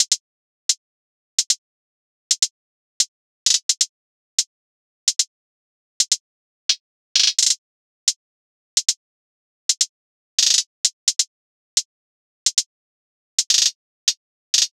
SOUTHSIDE_beat_loop_lime_hihat_130.wav